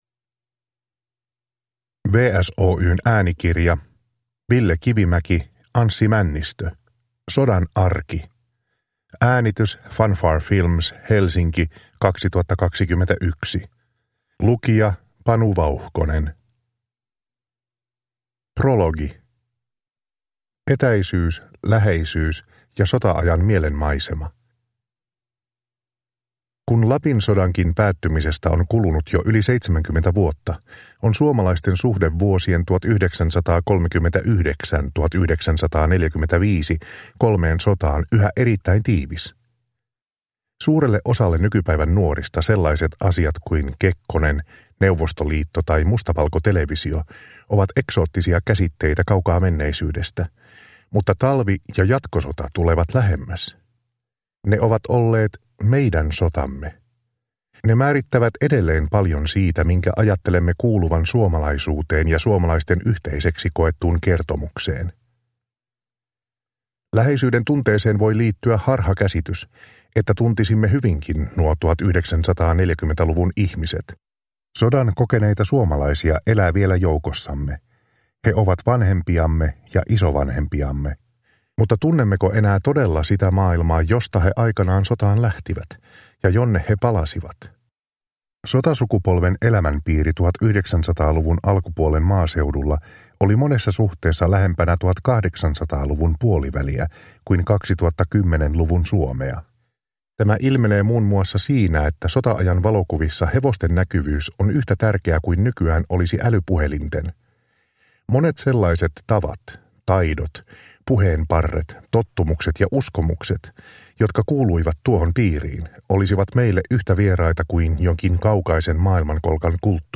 Sodan arki – Ljudbok – Laddas ner